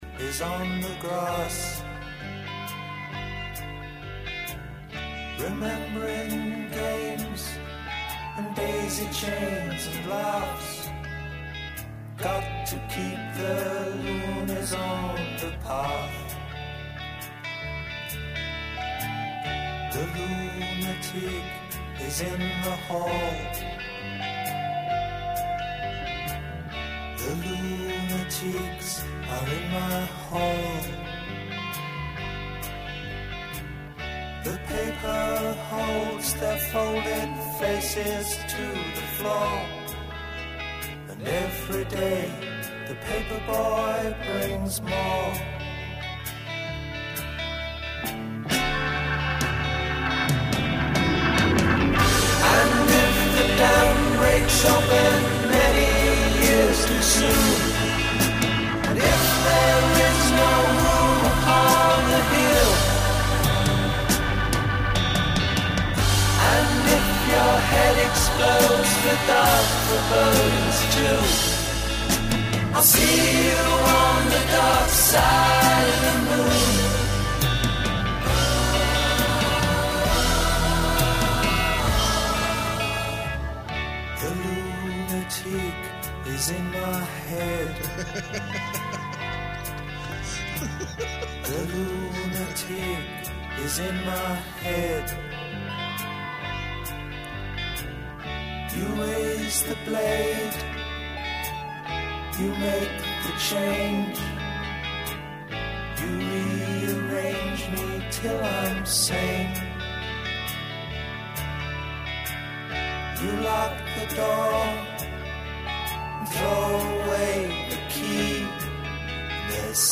Ya no hay trece sin martes como tampoco debe empezar a haber martes sin Caramuel, aunque sea para escuchar una playlist personal con tintes musicales indi.